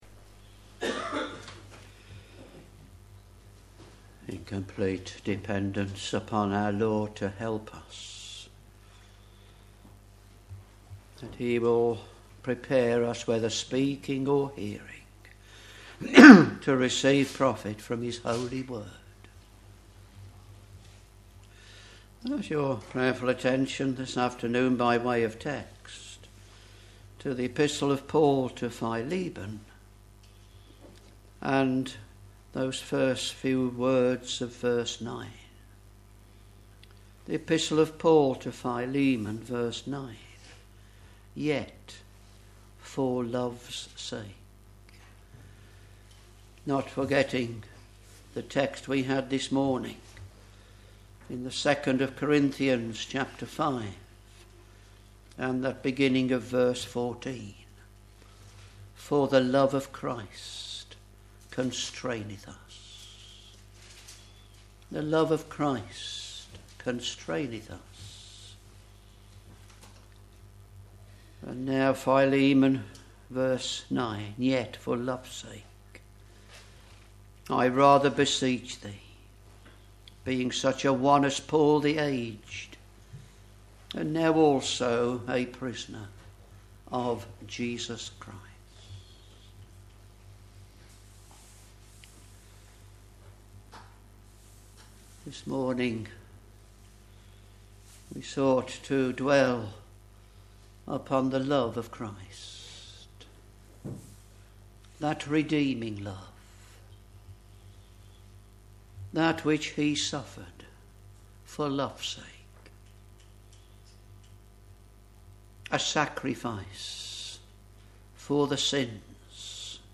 Sermons Philemon v.9 Yet for love's sake I rather beseech thee, being such an one as Paul the aged, and now also a prisoner of Jesus Christ.